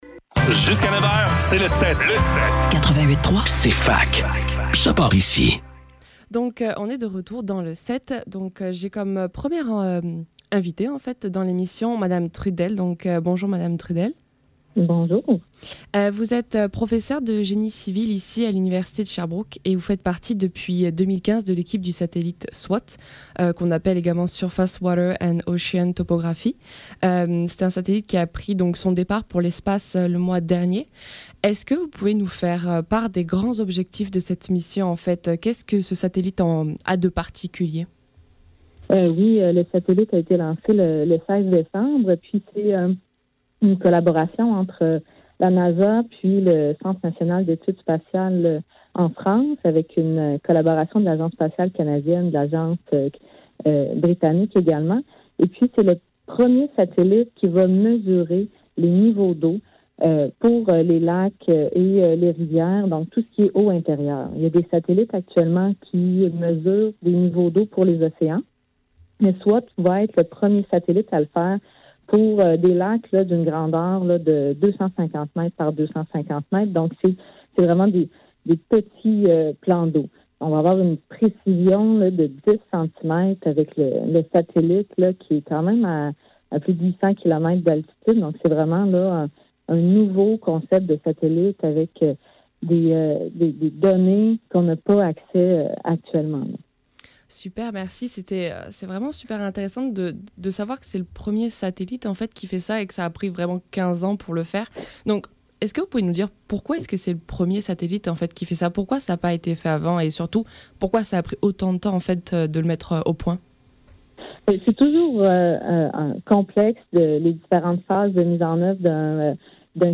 Le SEPT - Entrevue